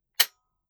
fps_project_1/30-30 Lever Action Rifle - Dry Trigger 003.wav at c10e3800f0dc663c0e4bb7d435fcb5798de681ee - fps_project_1 - Gitea: Git with a cup of tea